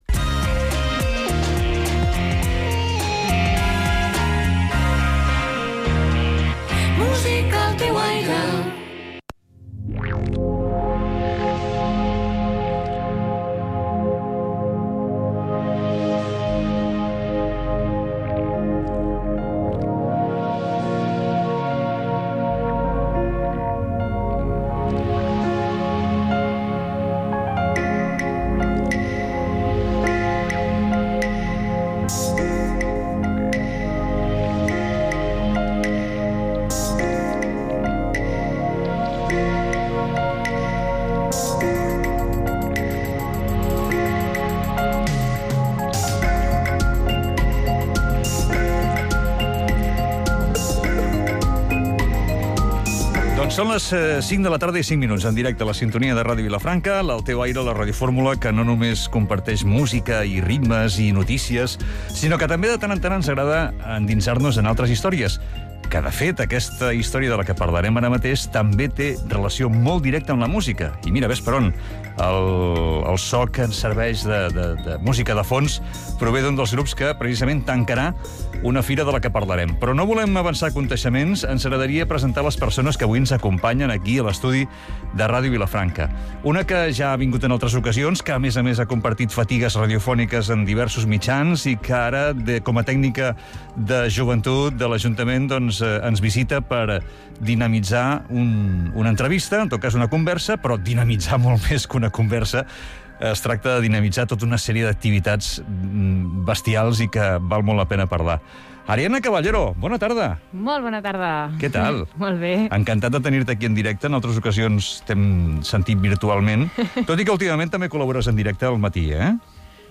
Radiofórmula
Entrevista fira l'Invisible 21-10-21